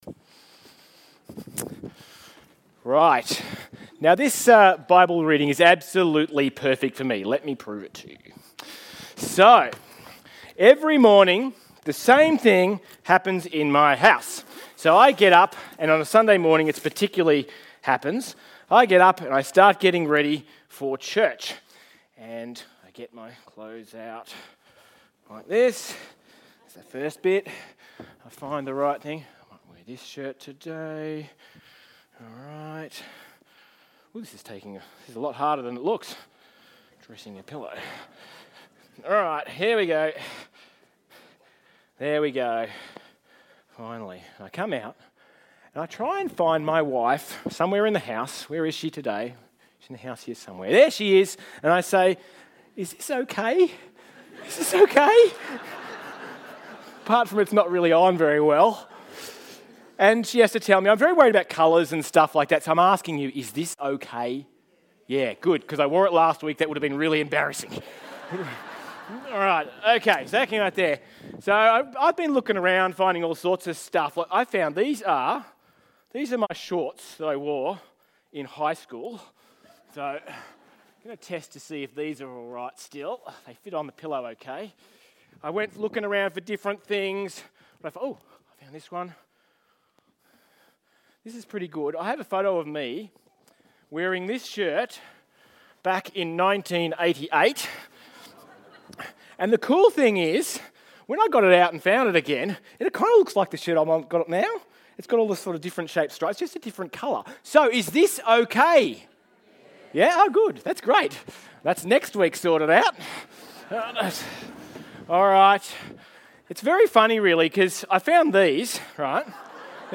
Sermons | St Alfred's Anglican Church